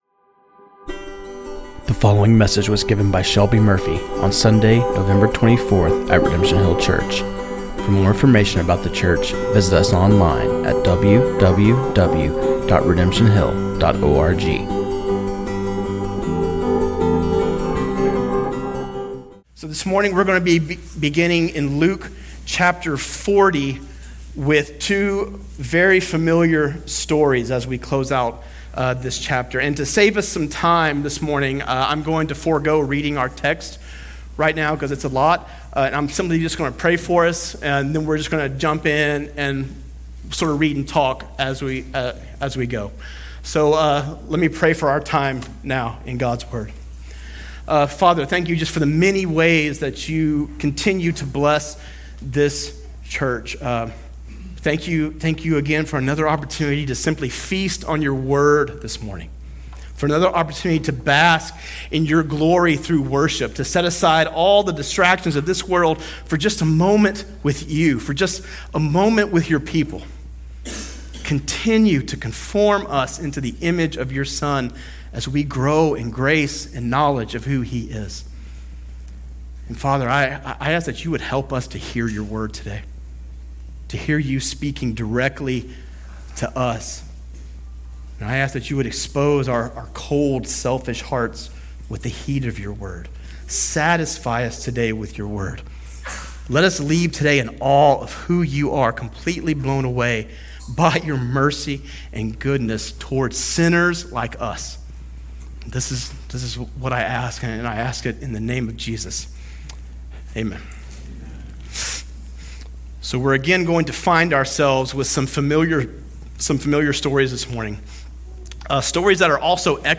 This sermon on Luke 8:40-56